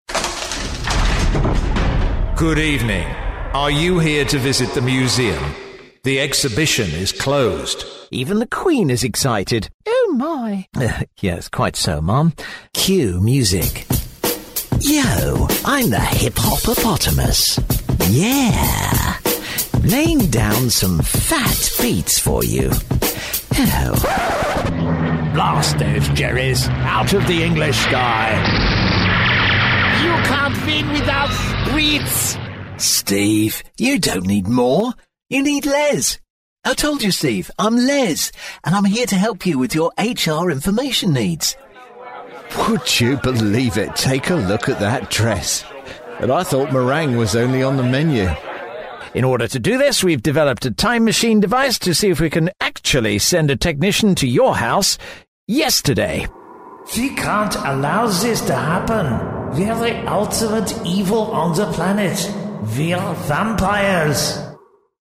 A selection of character voices as performed for everything from computer games to cartoons.